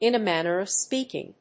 in a mánner of spéaking